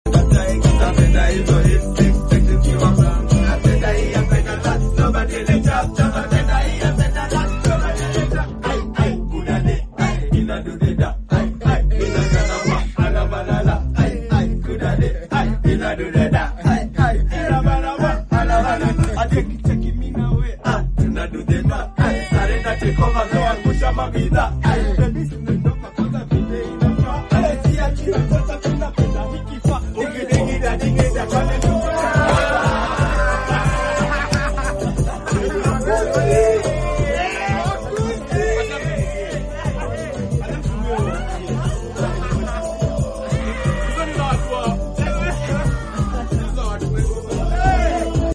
MAD PERFORMANCE AT THE ARBANTON FEST